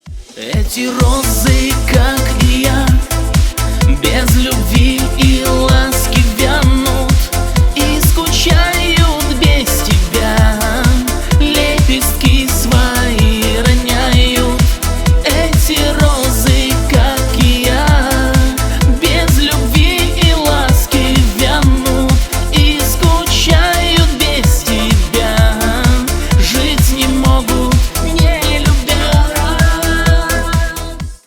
Шансон
грустные